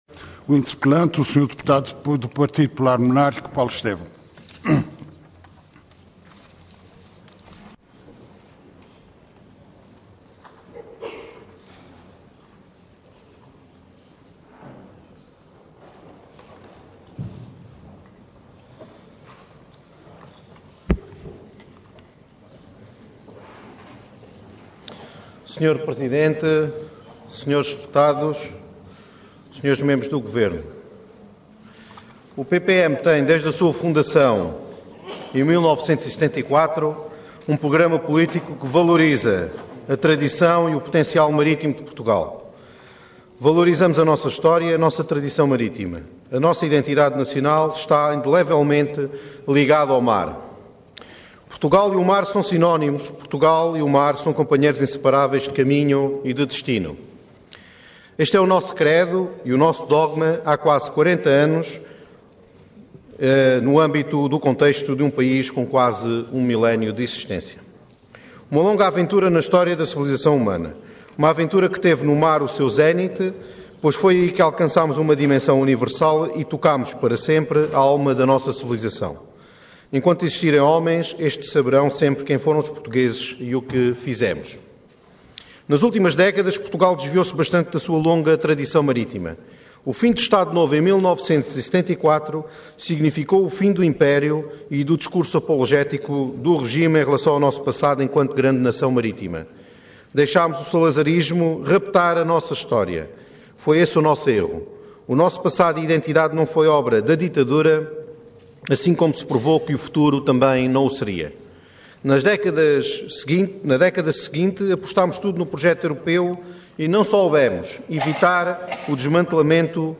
Intervenção Interpelação ao Governo Regional Orador Paulo Estêvão Cargo Deputado Entidade PPM